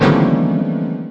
Animal Crossing Shocked Sound Effect